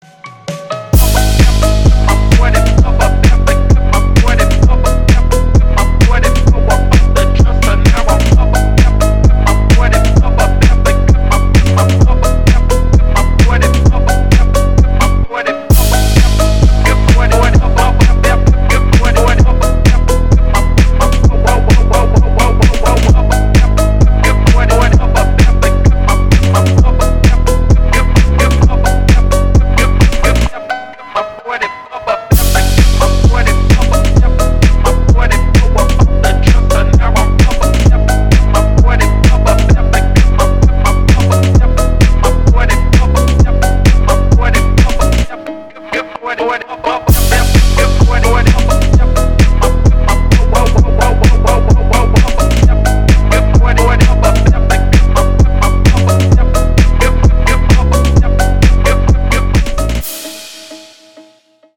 • Качество: 192, Stereo
мелодичные
без слов
качающие
четкие